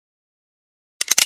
magnum_reload.mp3